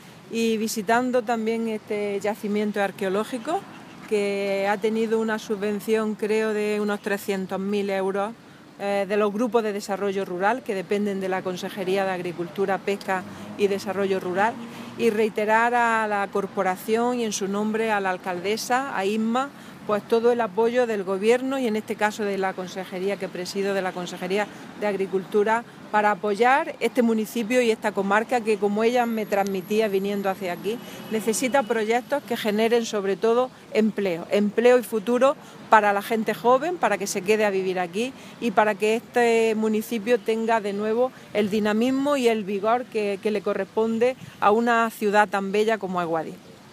Declaraciones de Carmen Ortiz sobre el proyecto de desarrollo rural para adecuar el teatro romano de Guadix a las visitas